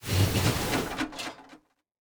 train-tie-4.ogg